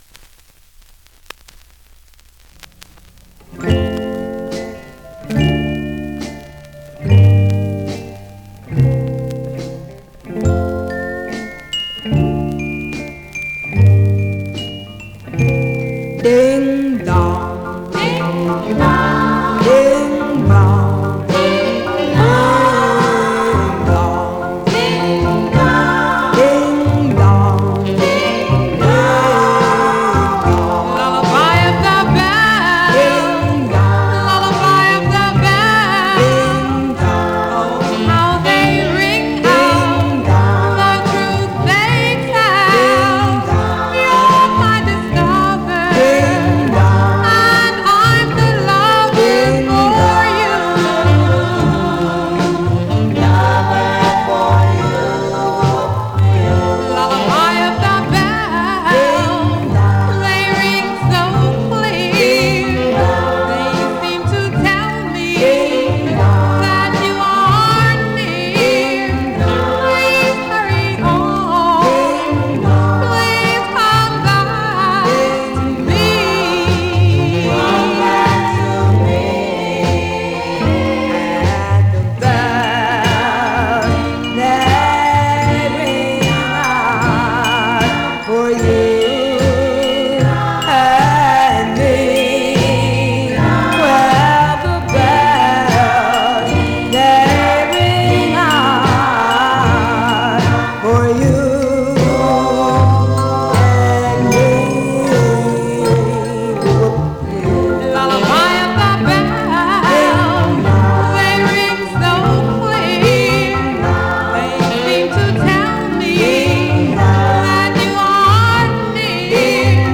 Some surface noise/wear Stereo/mono Mono
Black Female Group